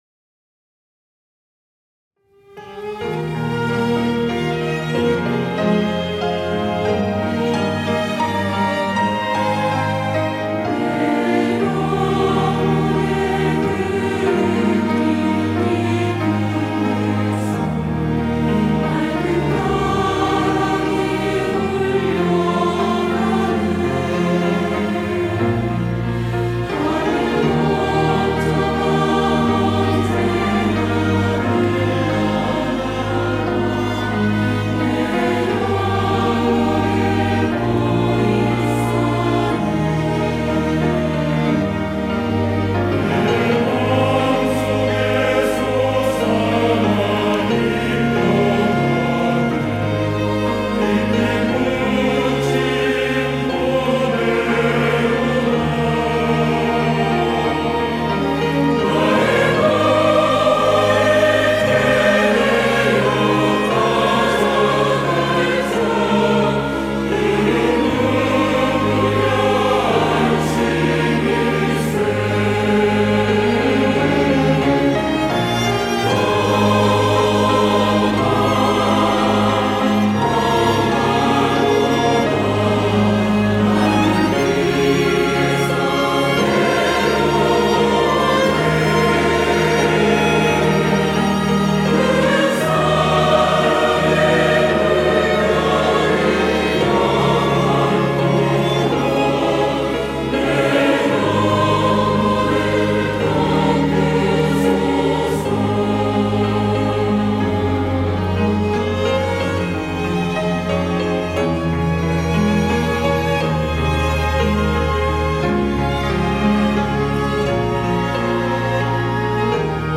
호산나(주일3부) - 내 영혼의 그윽히 깊은데서
찬양대 호산나